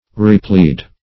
replead - definition of replead - synonyms, pronunciation, spelling from Free Dictionary Search Result for " replead" : The Collaborative International Dictionary of English v.0.48: Replead \Re*plead"\ (r?-pl?d"), v. t. & i. To plead again.